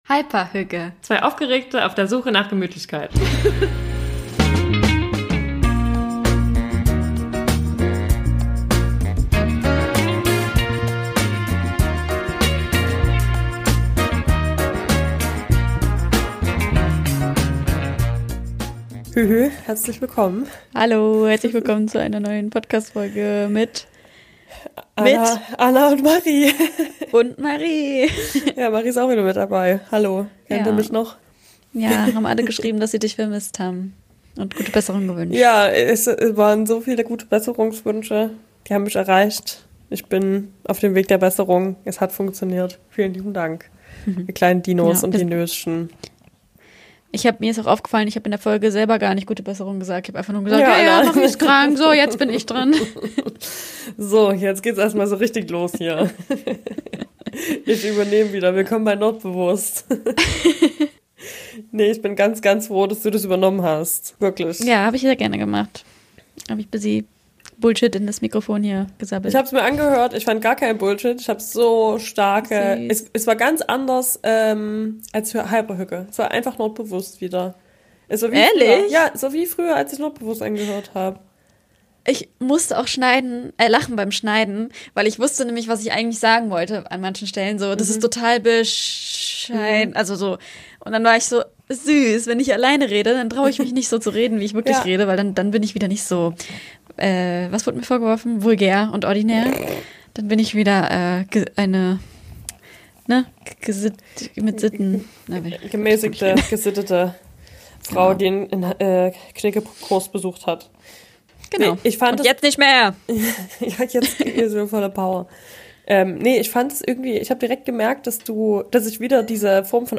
Was machen wir, wenn etwas einfach nicht in unserer Macht liegt? Wie trösten wir uns und wie bleiben wir trotzdem selbstwirksam? Ein ehrliches Gespräch übers Weitermachen.